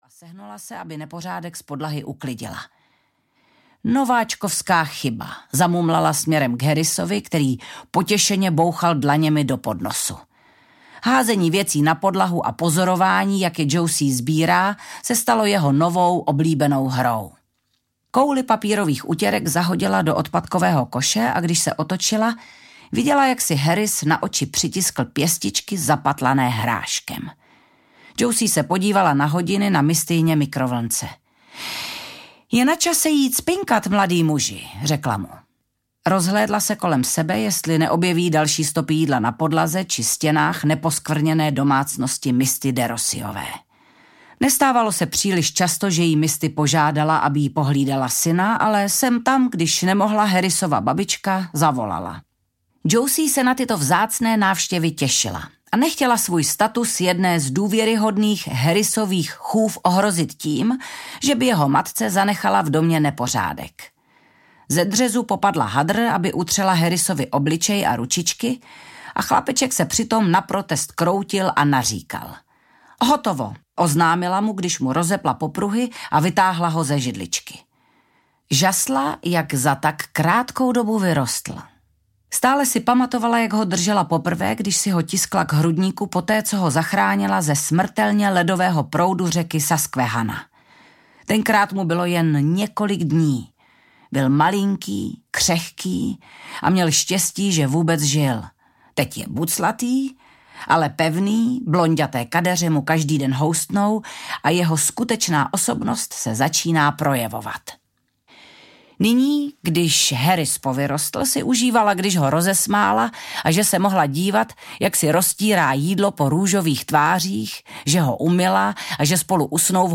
Matčin hrob audiokniha
Ukázka z knihy
• InterpretVanda Hybnerová